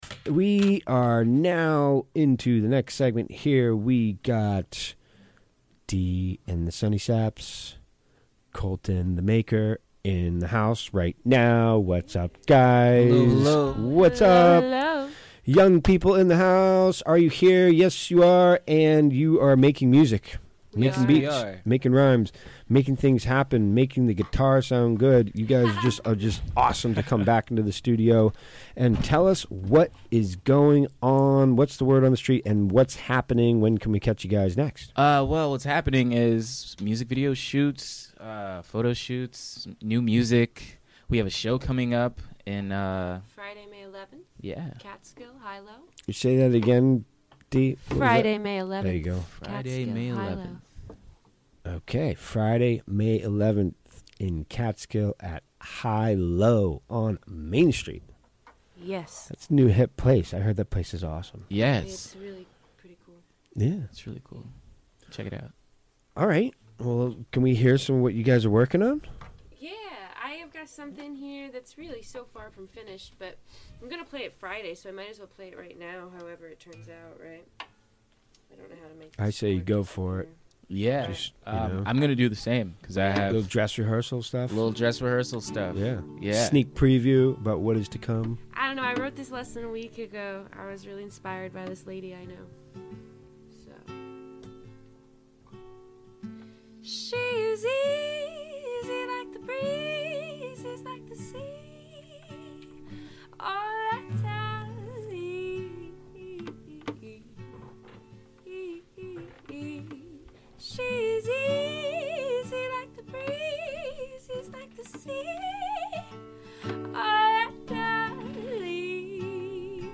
Recorded live on the WGXC Afternoon show on May 7, 2018.